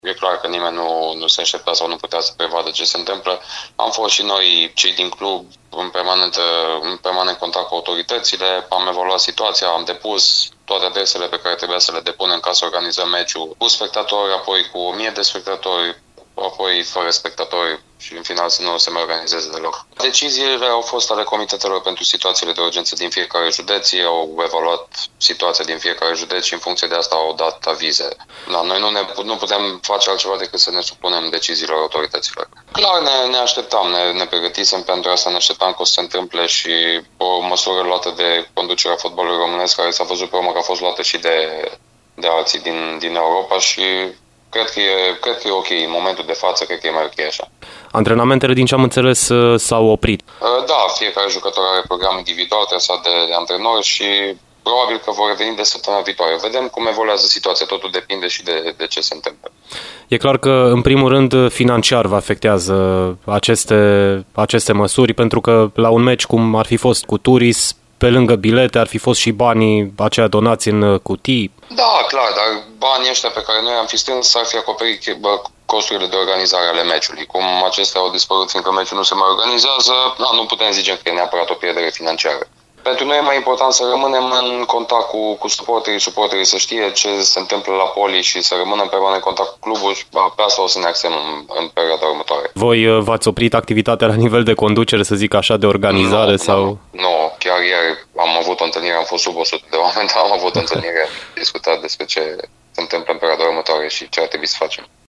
a vorbit, la Radio Timișoara, despre această perioadă: